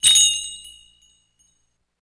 Doorbell
Bell, Drug Store, Clang On Open